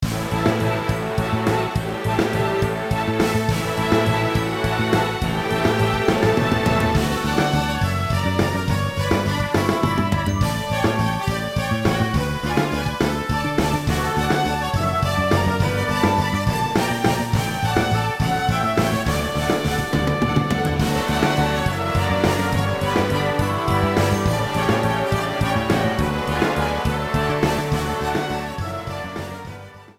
Trimmed and fade out
Fair use music sample